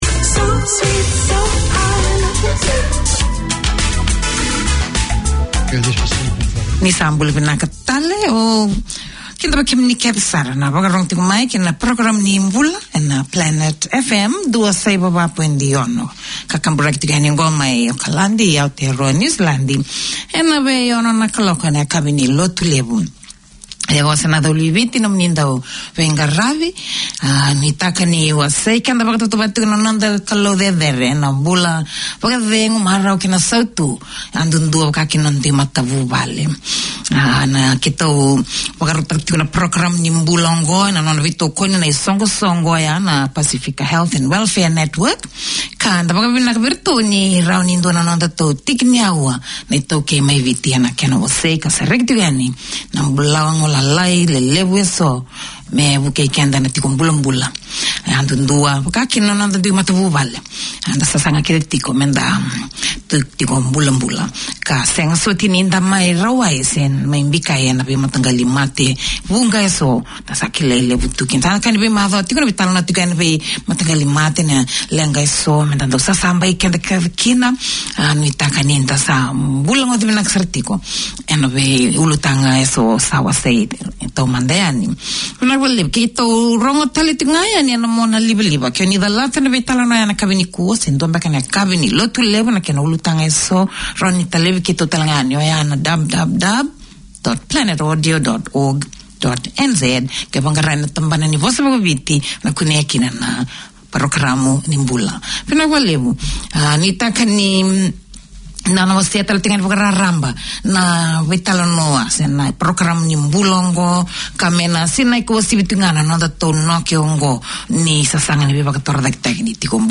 This is the weekly health check for Fijians. The 30 minute show covers healthy diet and physical exercise, discusses information about common diseases such as diabetes, asthma, heart health, high blood pressure, cold, flu and preventions. And there's a healthy dose of popular Fijian music.